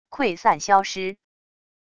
溃散消失wav音频